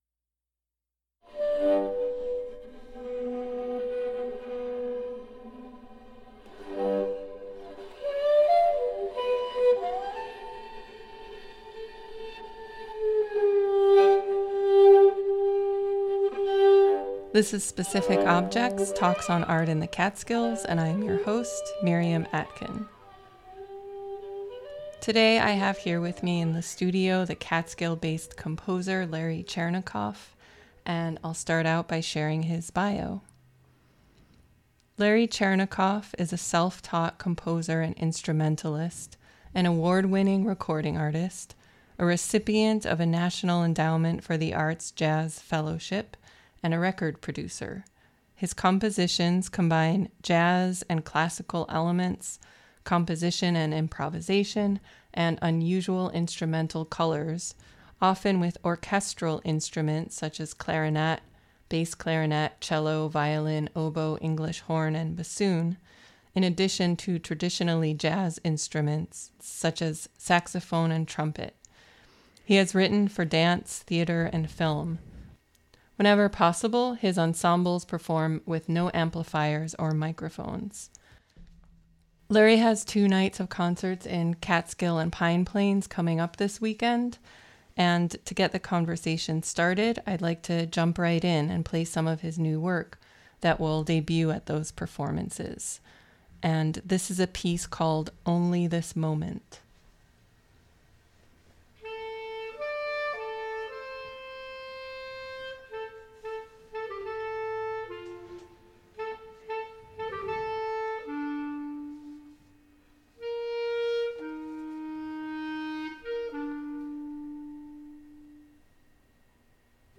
Specific Objects is a monthly freeform discussion